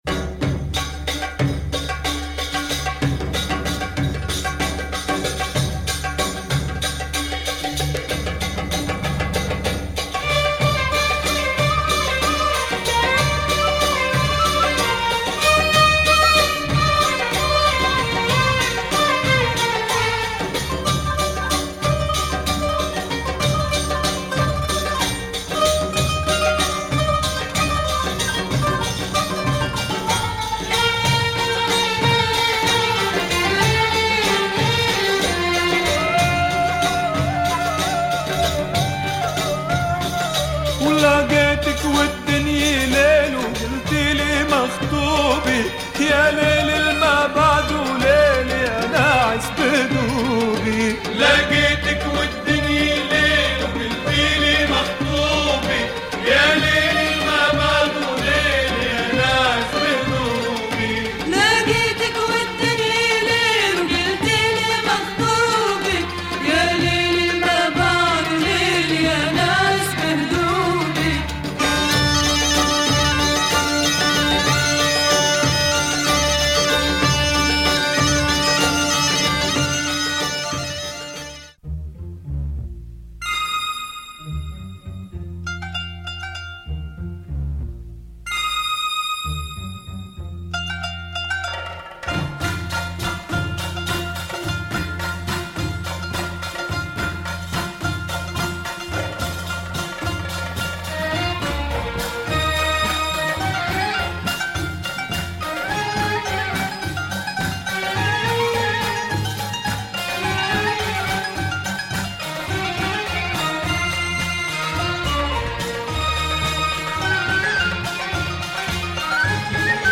Kind of Lebanese play with music
very good arabic percussion breaks